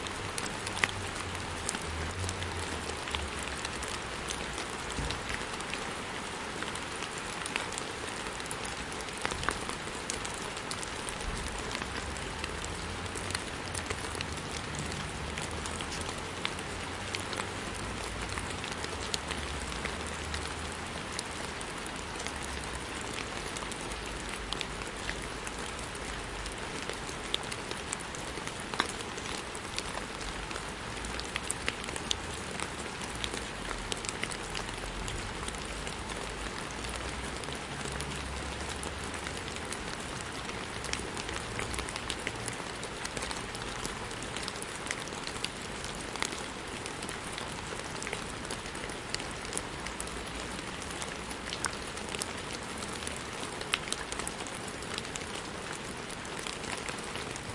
8分钟的雨 " 雨在草地上pt
描述：在暴雨期间，雨滴下滴在草，关闭。
Tag: 环境 ATMO 暴雨 天气 现场记录